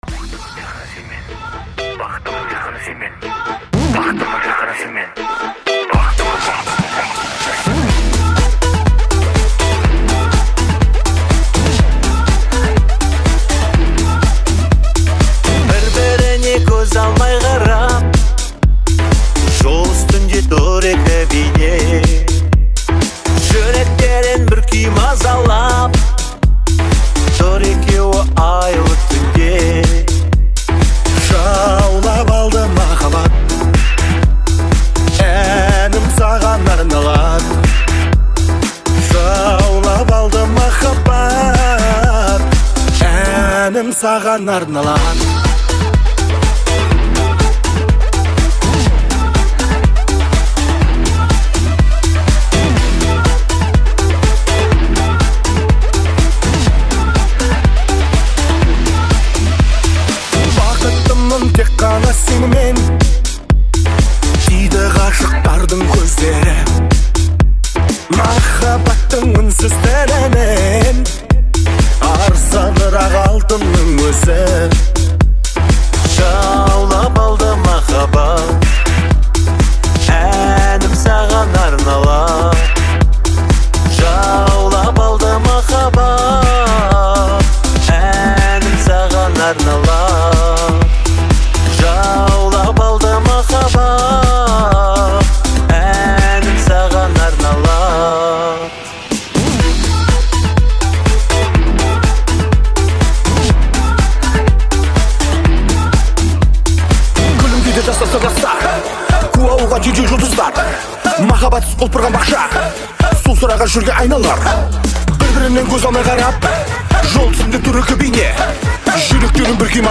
энергичный трек
мелодичные вокалы
яркими ритмами, создавая атмосферу праздника и веселья